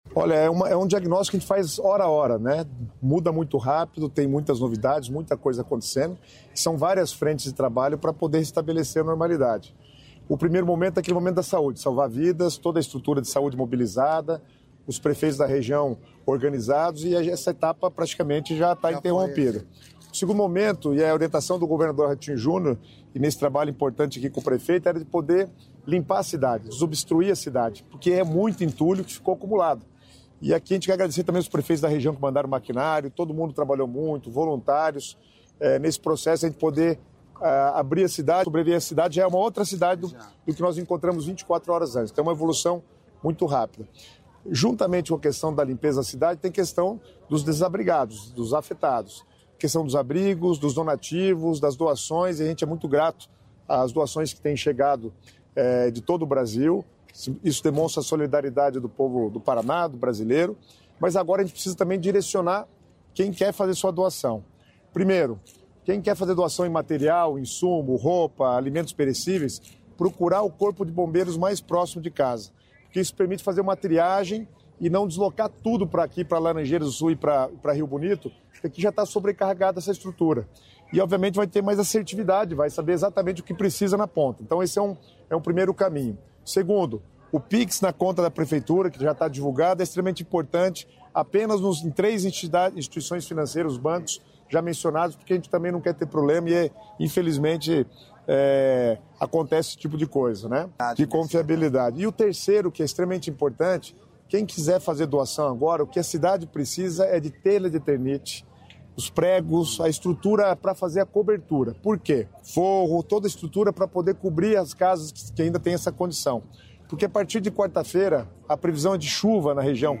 Sonora do secretário estadual das Cidades, Guto Silva, sobre a situação e as ações em Rio Bonito do Iguaçu